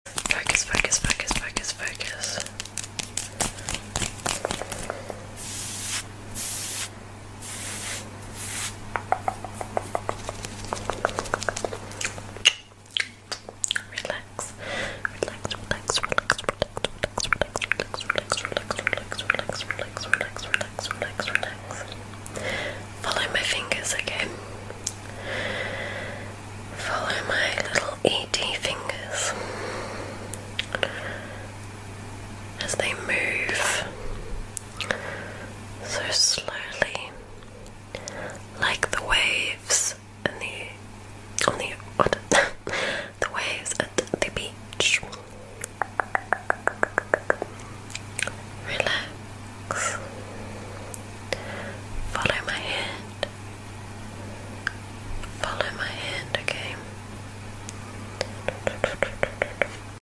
Upload By Asmr